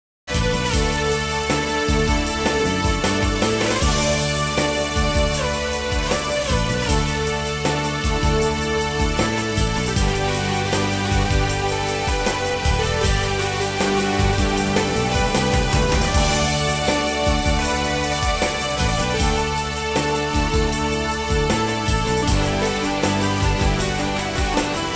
rock intro outlining the events